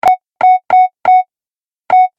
دانلود آهنگ کیبورد 21 از افکت صوتی اشیاء
جلوه های صوتی
دانلود صدای کیبورد 21 از ساعد نیوز با لینک مستقیم و کیفیت بالا